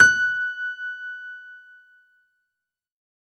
F#5  DANCE-L.wav